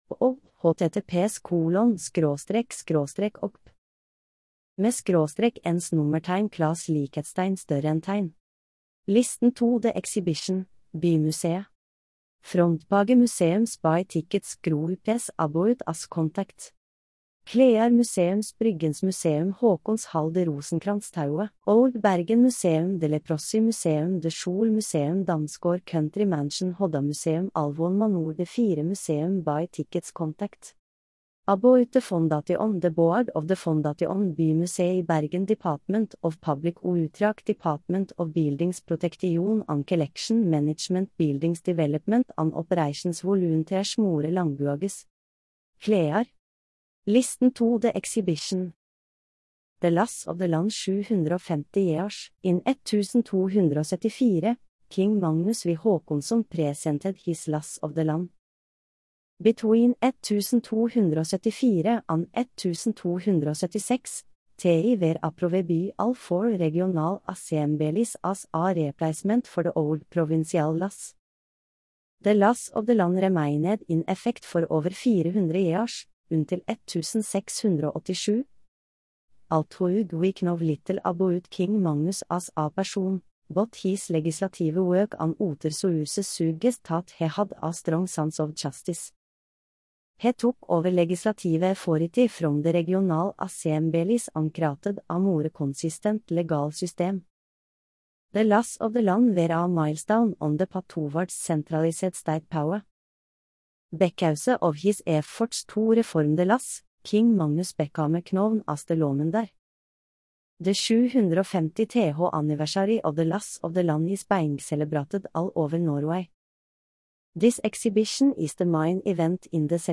Listen to the content 0:00 0:00 1.0x × Generated with Mementor's Text to Speech for WordPress plugin , using ElevenLabs technology.